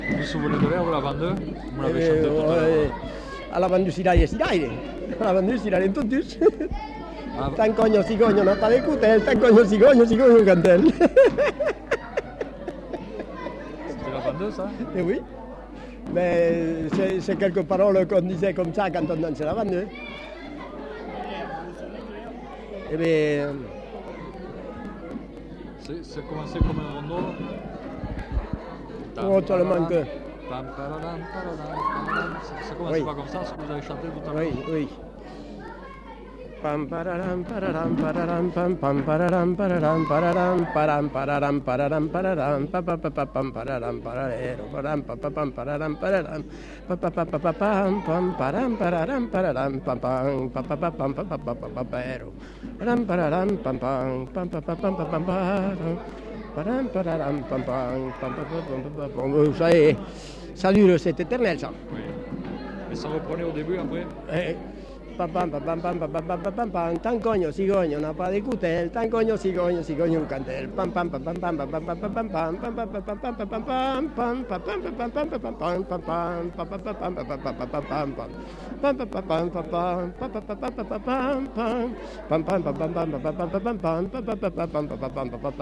Avant-deux Centre culturel.